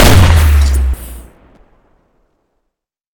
shoot4.wav